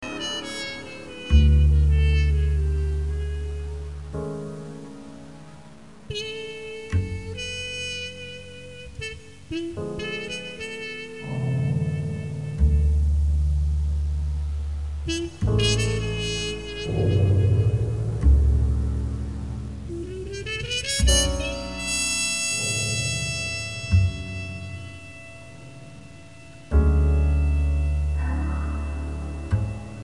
tromba, flicorno
alto sax
pianoforte
contrabbasso
batteria
chitarra
elettronica, sound programming